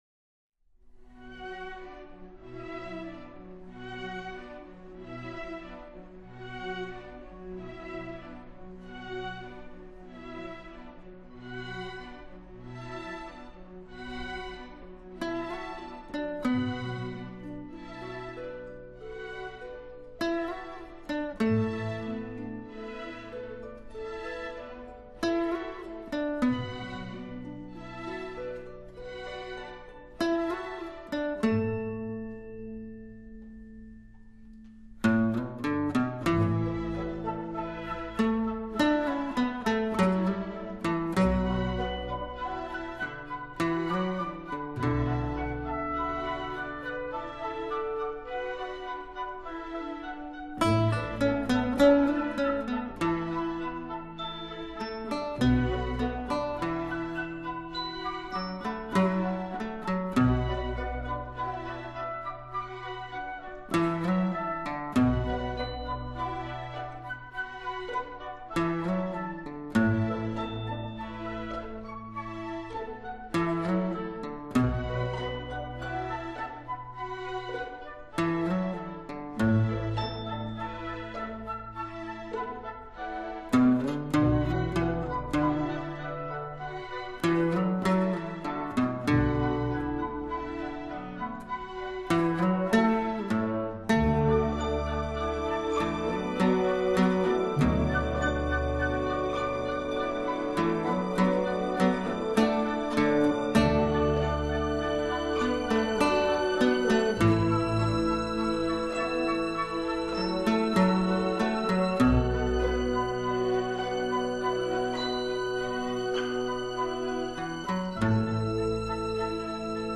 乐曲营造出的恬静和谐氛围